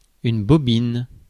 Ääntäminen
France : « une bobine »: IPA: [yn bɔ.bin]